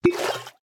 assets / minecraft / sounds / item / bottle / fill3.ogg